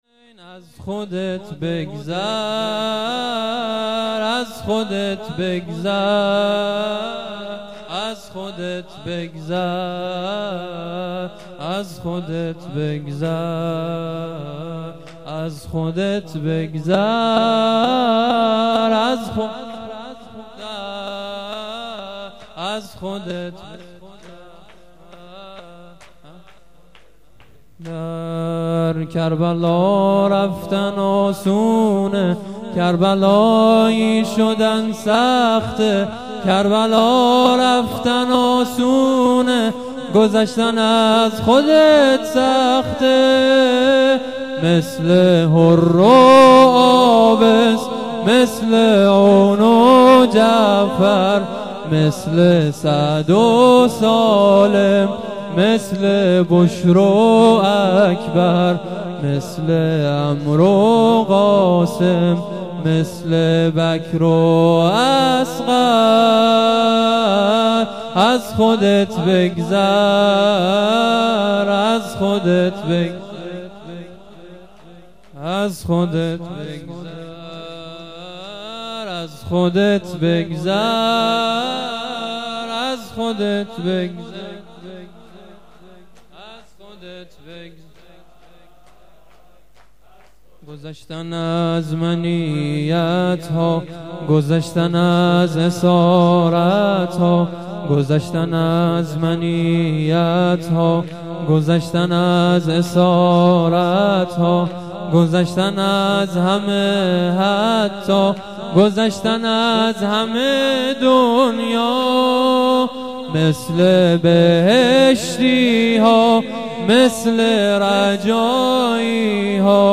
شور
جلسه هفتگی ۲۳ دی ماه ۱۴۰۰